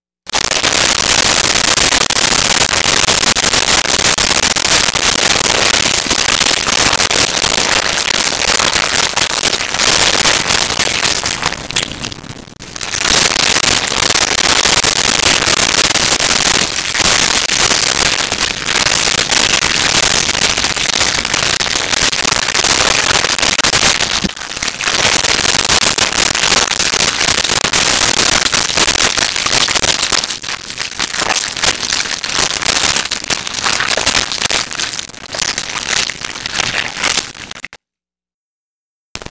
Beetle Movement
Beetle Movement.wav